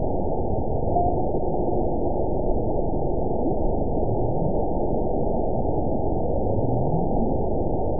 event 921812 date 12/19/24 time 07:02:23 GMT (10 months, 1 week ago) score 9.52 location TSS-AB02 detected by nrw target species NRW annotations +NRW Spectrogram: Frequency (kHz) vs. Time (s) audio not available .wav